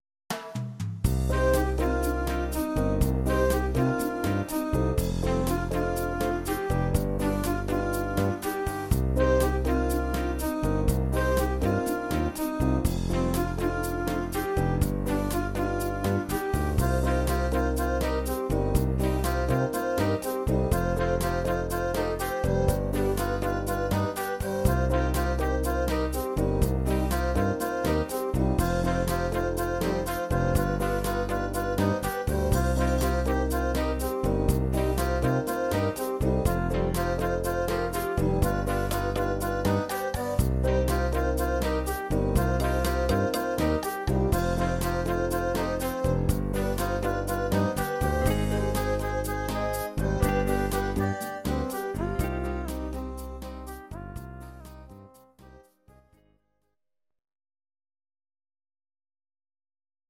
Audio Recordings based on Midi-files
Pop, Ital/French/Span, 2010s